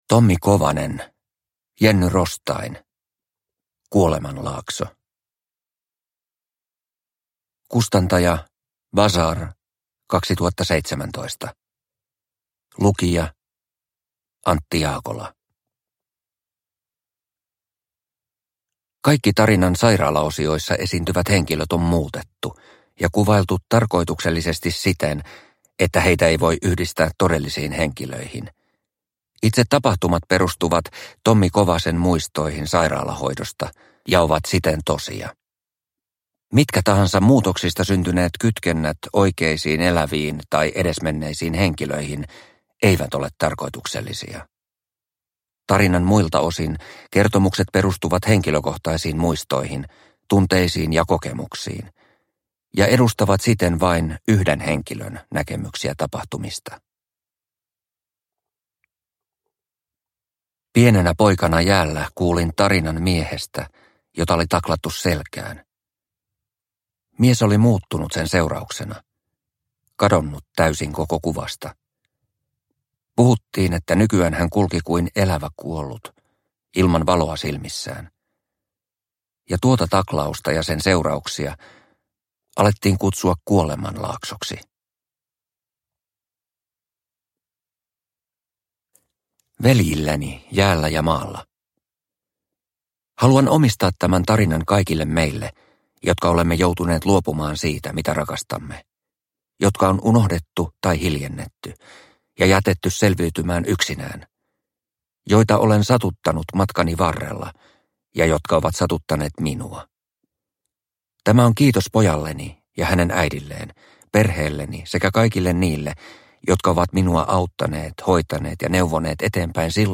Kuolemanlaakso – Ljudbok – Laddas ner